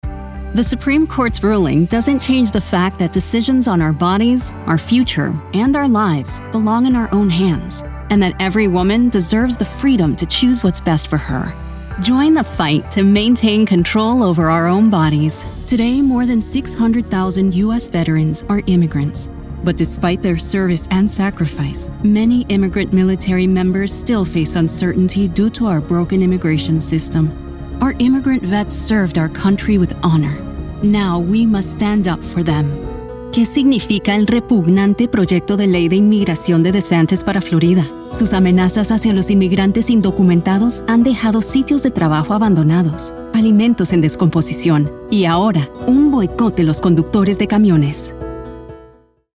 Englisch (US)
Weiblich
Politische Anzeigen
Meine Stimme wird als einnehmend, ehrlich, wohltuend, ausdrucksstark und beruhigend für Unternehmen, E-Learning, IVR oder Erzählungen beschrieben.
Gesangskabine